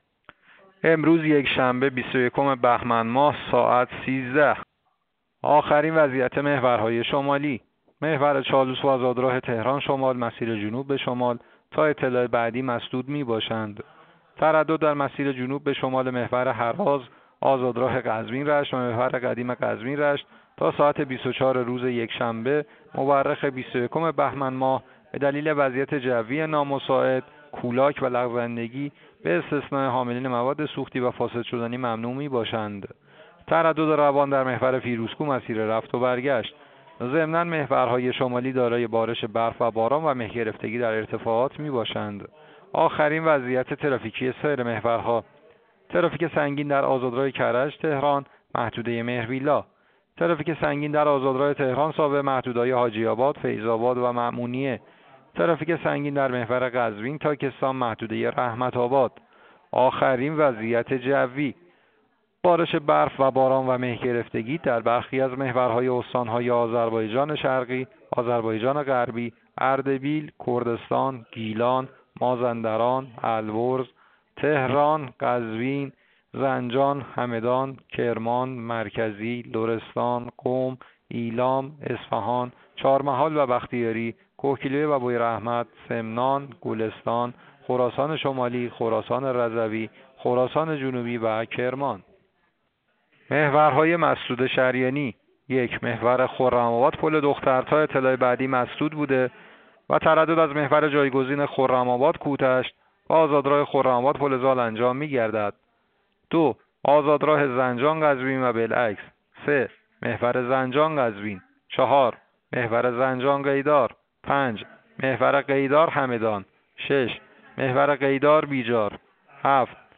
گزارش رادیو اینترنتی از آخرین وضعیت ترافیکی جاده‌ها ساعت ۱۳ بیست یکم بهمن؛